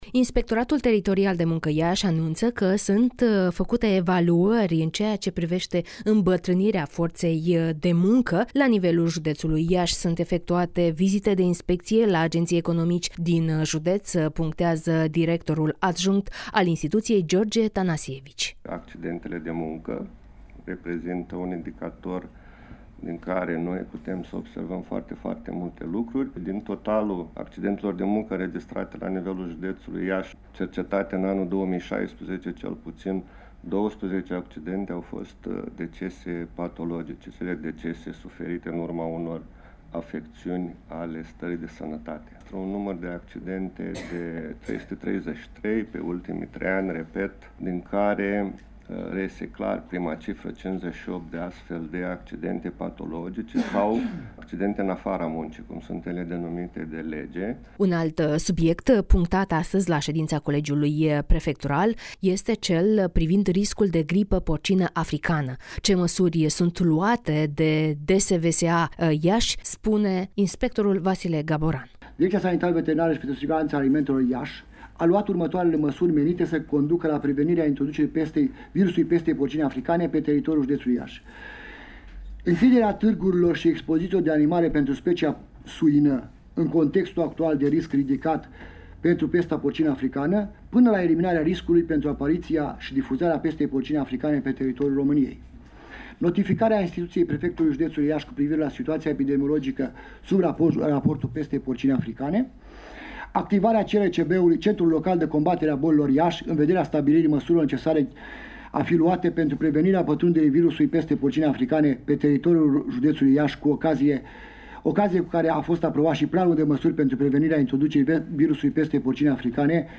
(REPORTAJ) Măsuri privind riscul de pestă porcină africană pe teritoriul județului Iași